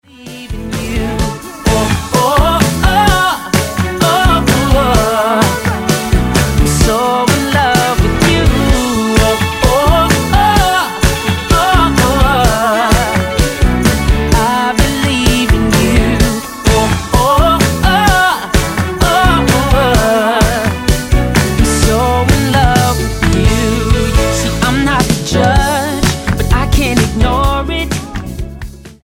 eclectic neo-soul gospel hitmaker
Style: Gospel